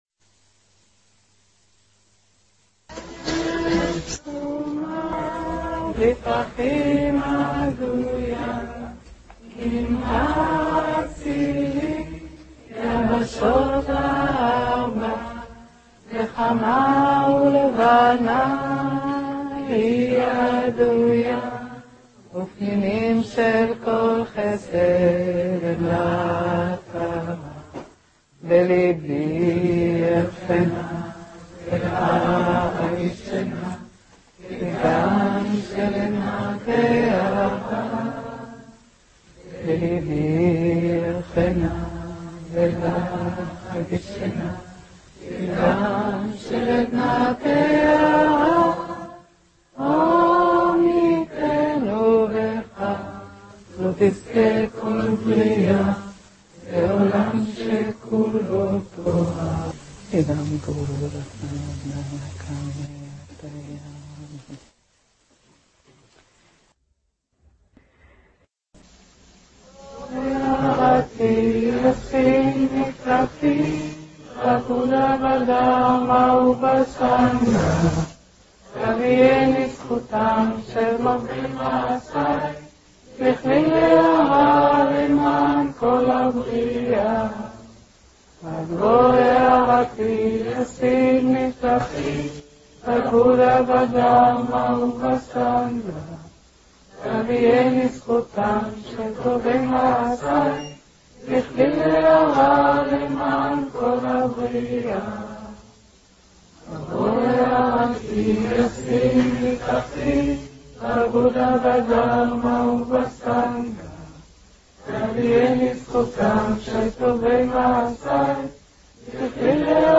הקלטות מקורס